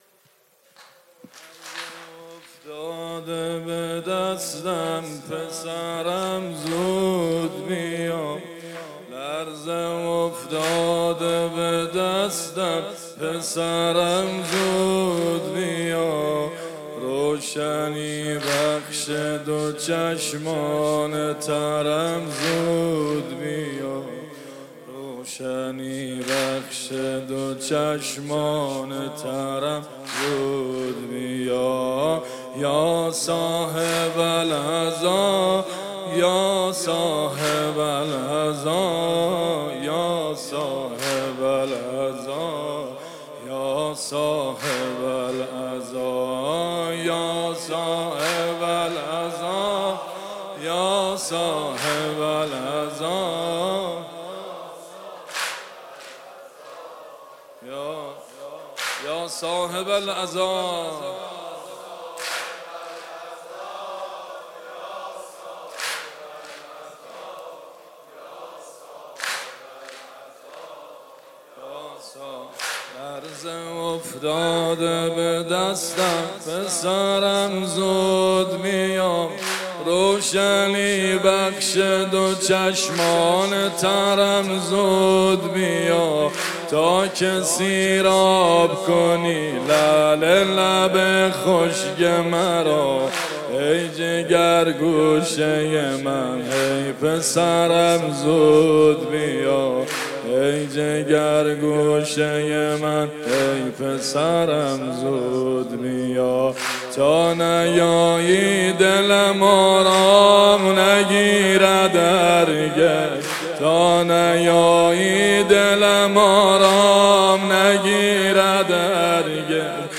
نوحه‌خوانی